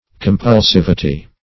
Meaning of compulsivity. compulsivity synonyms, pronunciation, spelling and more from Free Dictionary.